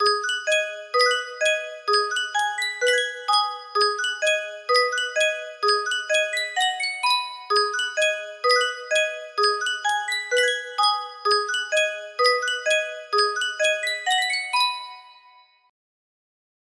Yunsheng Music Box - Unknown Tune 1112 music box melody
Full range 60